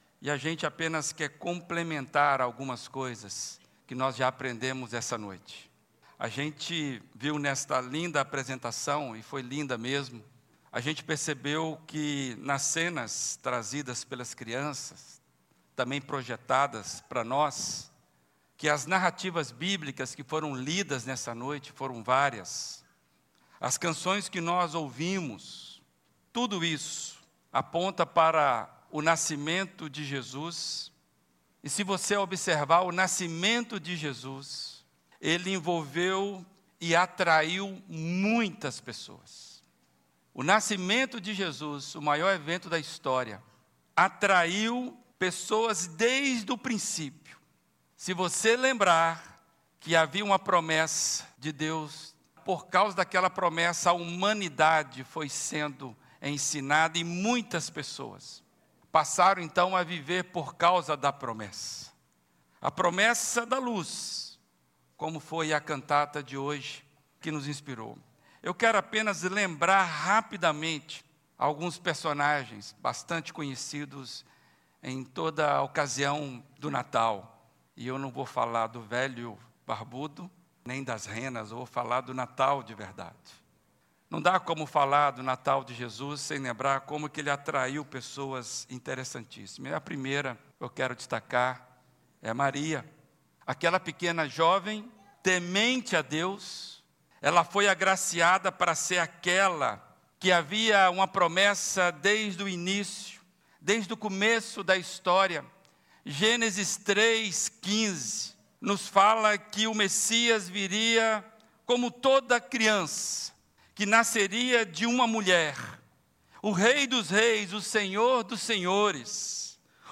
Primeira Igreja Batista de Brusque